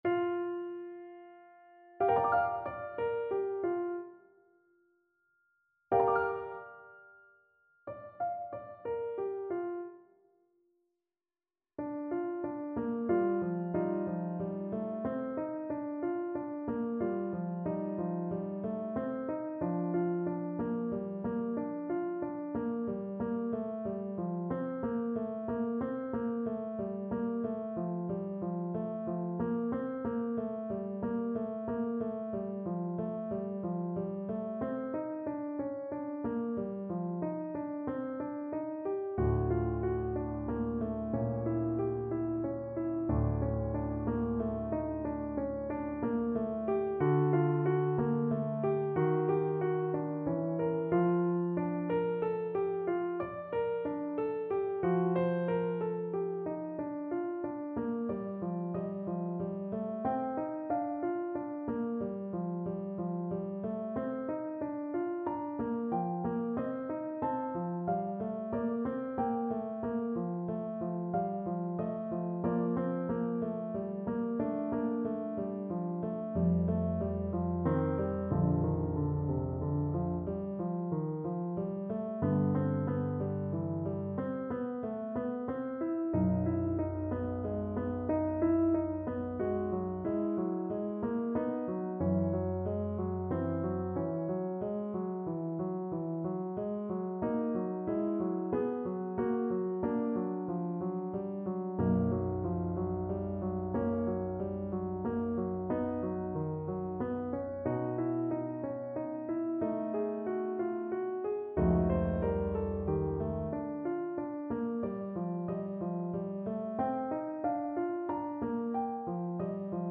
Classical Mahler, Gustav Ich atmet' einen linden Duft No.2 from Ruckert Lieder Alto Saxophone version
Play (or use space bar on your keyboard) Pause Music Playalong - Piano Accompaniment Playalong Band Accompaniment not yet available transpose reset tempo print settings full screen
Alto Saxophone
6/4 (View more 6/4 Music)
Bb major (Sounding Pitch) G major (Alto Saxophone in Eb) (View more Bb major Music for Saxophone )
Lento =92
Classical (View more Classical Saxophone Music)